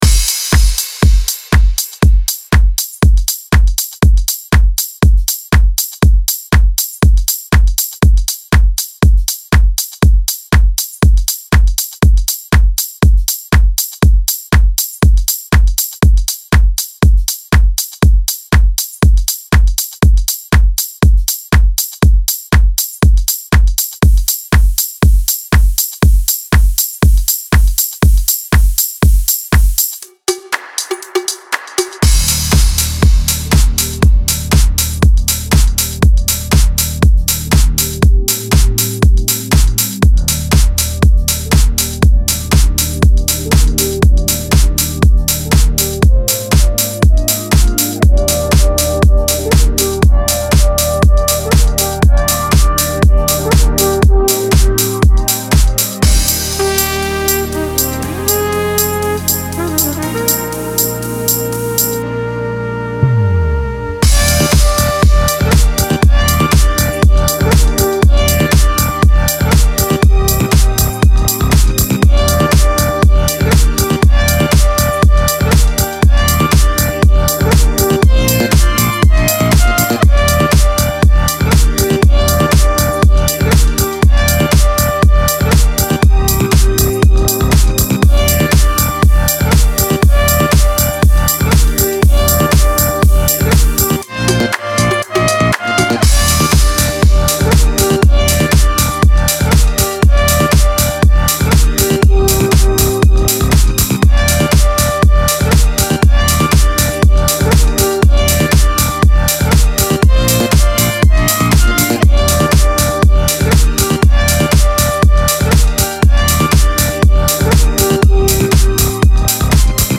Brand new remix out right now!